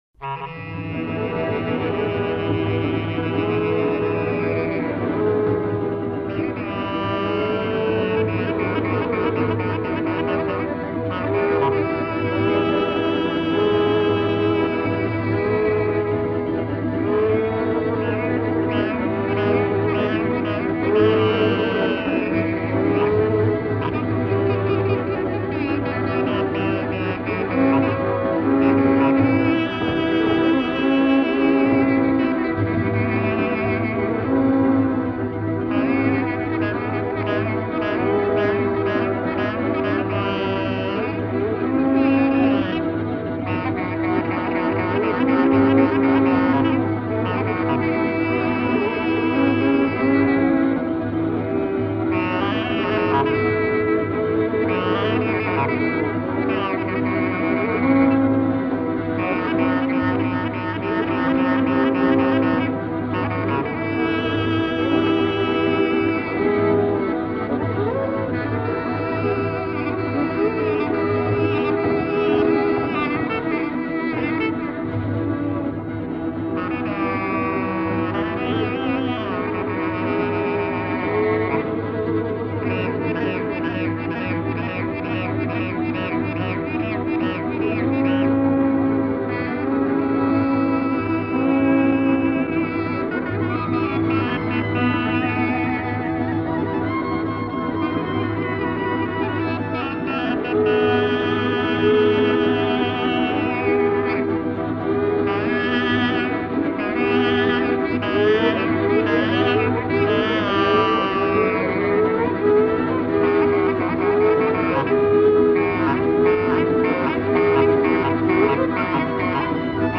Gërnetari
Kaba e tipit dypjesëshe: kaba e valle.
Pjesa e parë e kabasë shtjellohet si kaba lirike toske, kjo e ndërthurur me veçoritë e fakturës së valles isopolifonike myzeqare të burrave.
Pjesa e mesit merr ngjyrimet e forta të vajtimit ku violina e thekson akoma më shumë tonin elegjiak përmes daljes në regjistrin e sipërm të saj, megjithëse në rolin e prerësit.
Pjesa e dytë, valle, ka disa shtresa melodike, midis tyre ato toske, labe dhe minoritare.